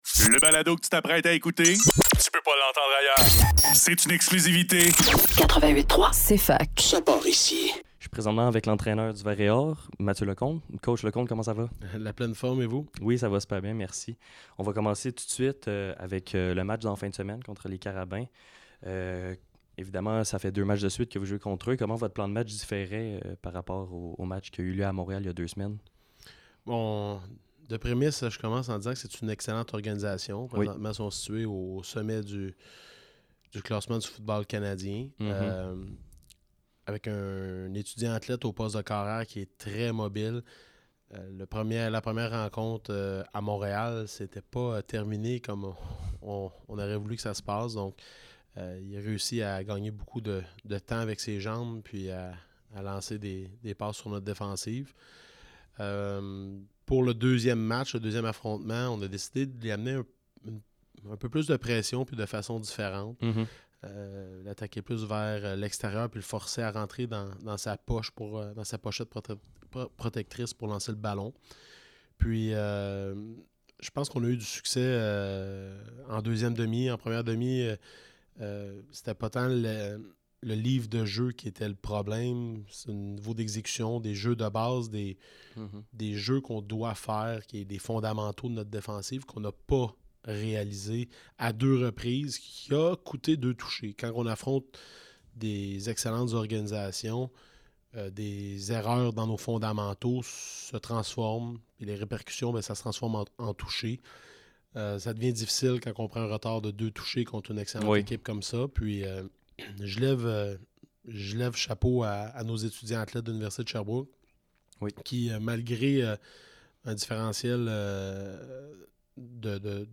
Le DOUZE - Chronique Vert & Or - Entrevue